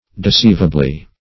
deceivably - definition of deceivably - synonyms, pronunciation, spelling from Free Dictionary Search Result for " deceivably" : The Collaborative International Dictionary of English v.0.48: Deceivably \De*ceiv"a*bly\, adv. In a deceivable manner.